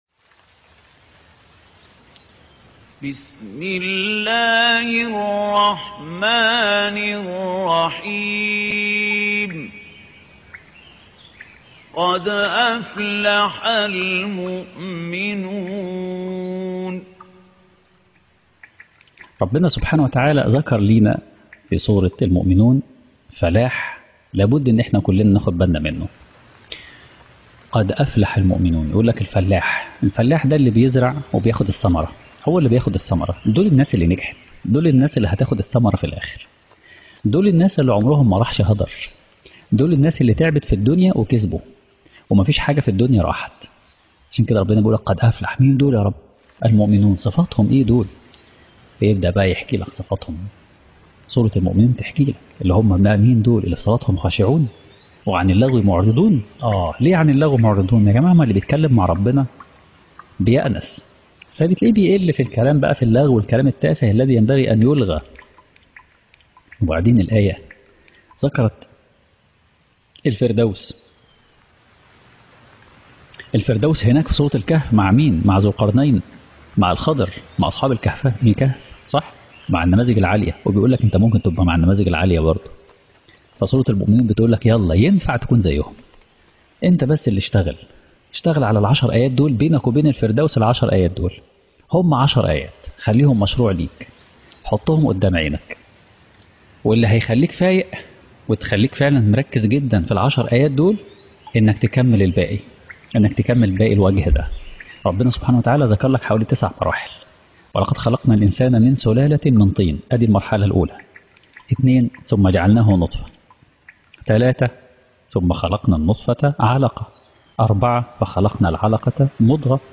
عنوان المادة قد أفلح المؤمنون _ الجزء الثامن عشر _ قرأ الإمام